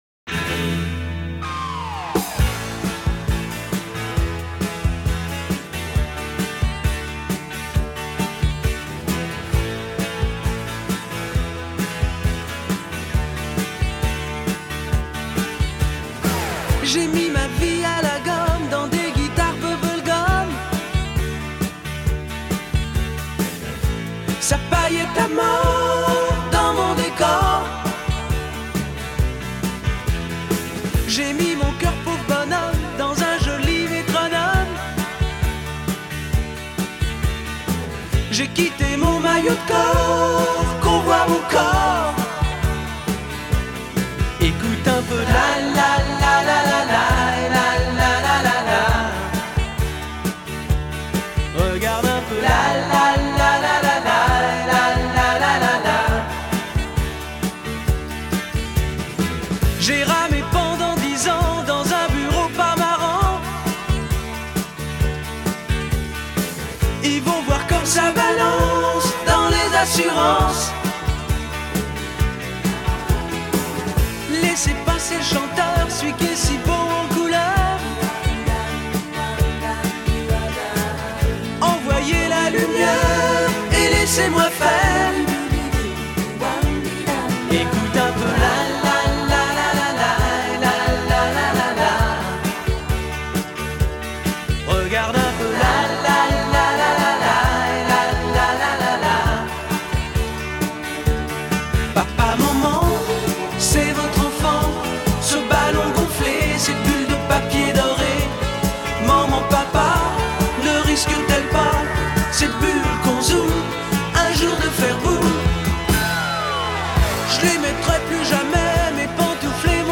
Genre: Pop Rock, Synth-pop, Disco